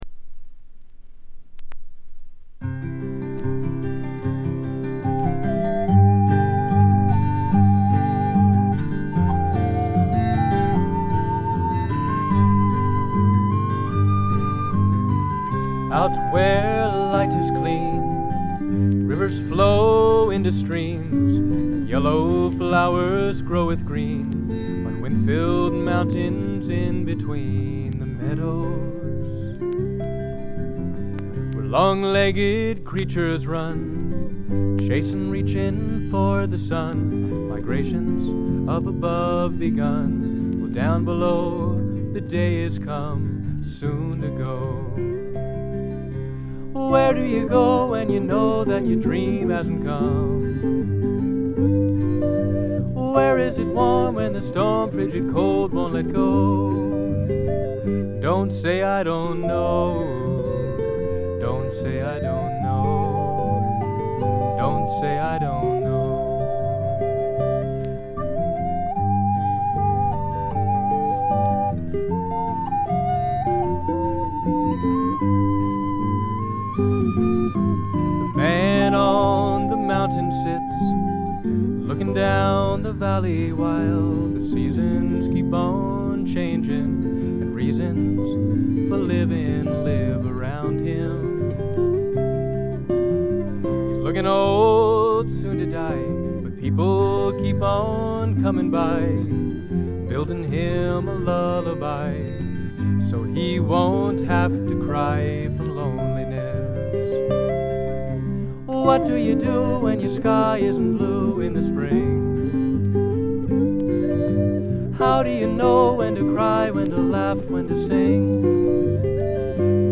プレスが良くないのか、チリプチやや多め（特にB1）。特に目立つノイズはありません。
全編を通じて見事にサイレントで聖なる世界が展開します。ドリーミーで夢うつつなとろとろ具合が見事です。
ずばりドリーミーフォークの名作と言って良いでしょう。
試聴曲は現品からの取り込み音源です。
Violin